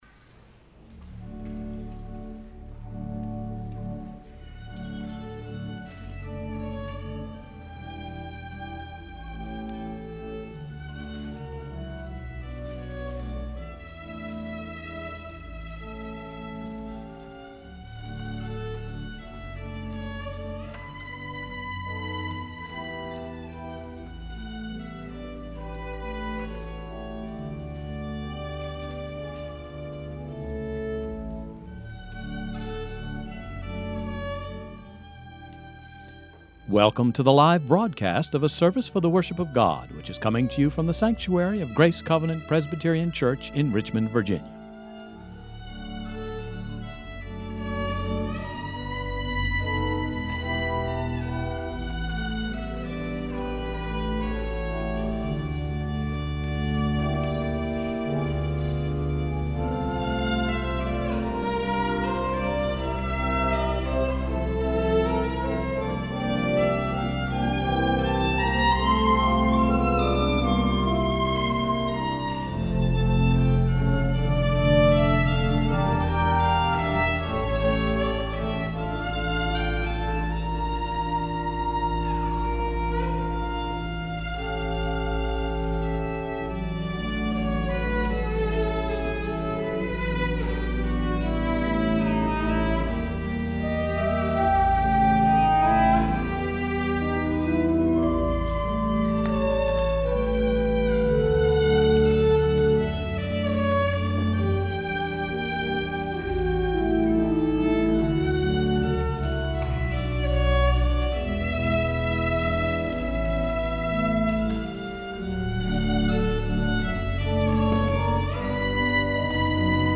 violin
organ